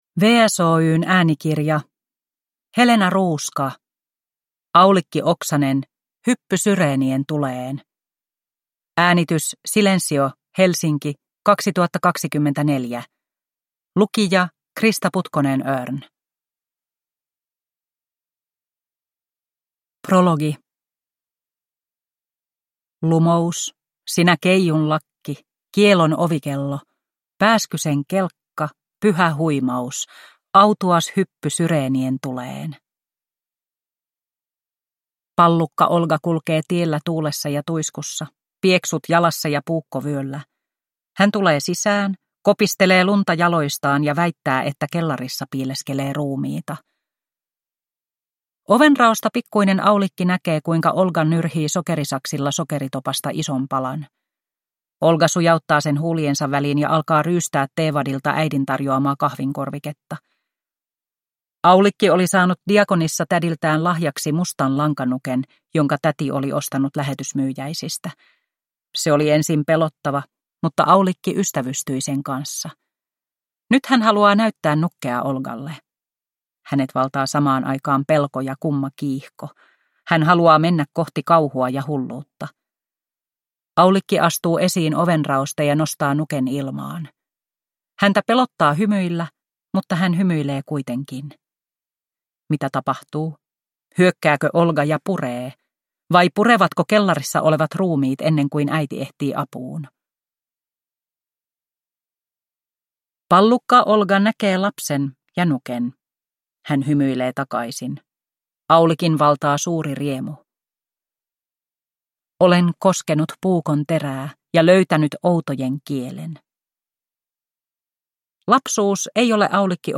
Aulikki Oksanen. Hyppy syreenien tuleen – Ljudbok